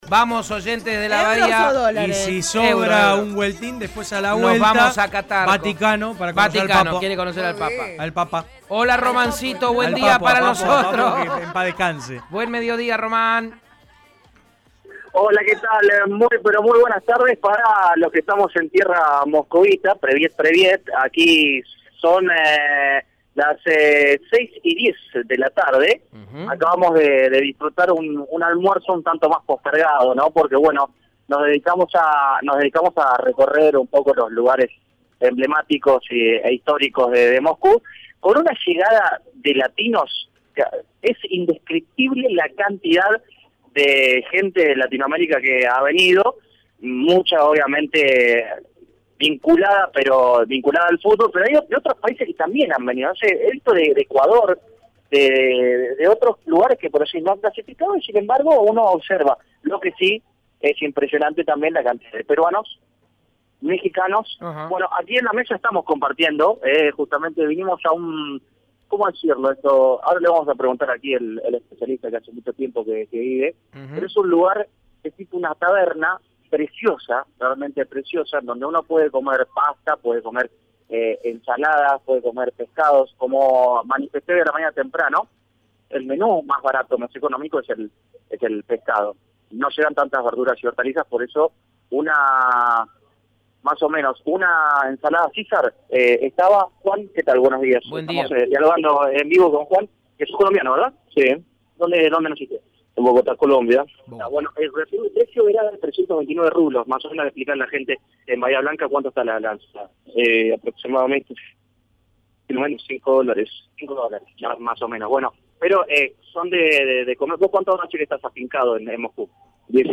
Desopilante comunicación desde Rusia